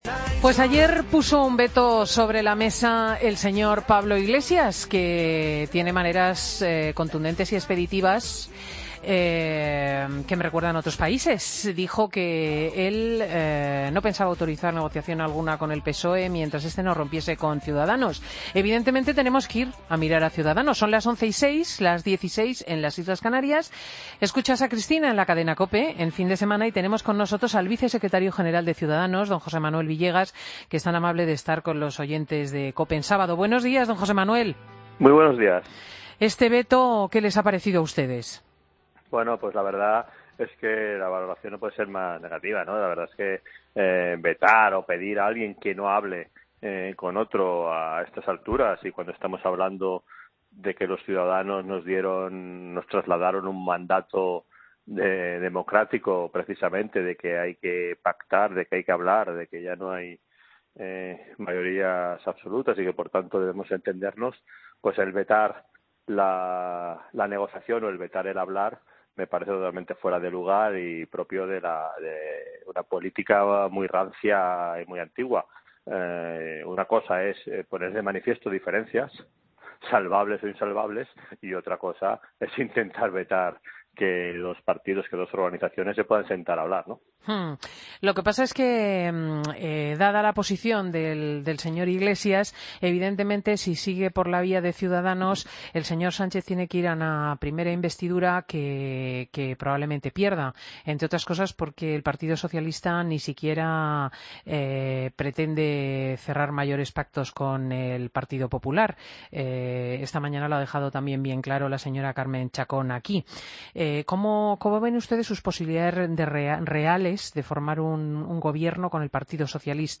Entrevista a José Manuel Villegas, Vicesecretario General de Ciudadanos y portavoz adjunto en el Congreso, en Fin de Semana Cope.